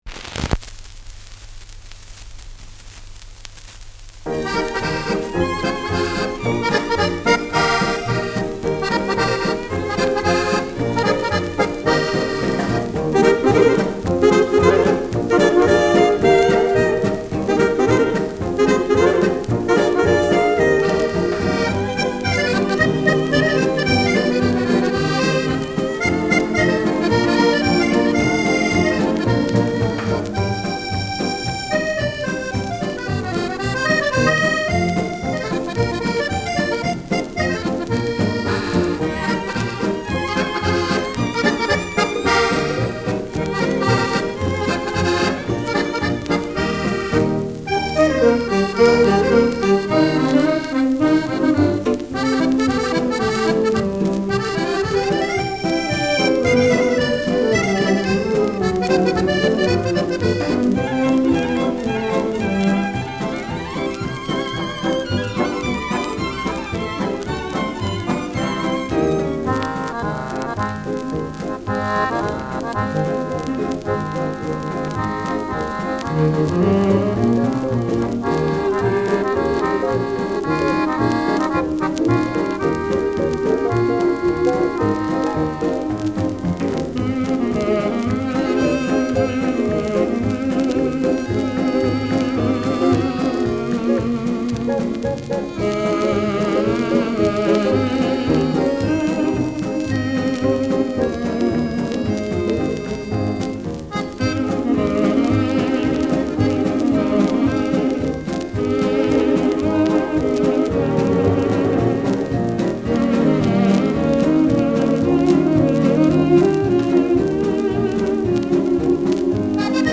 Schellackplatte
Abgespielt : Durchgehend leichtes Knacken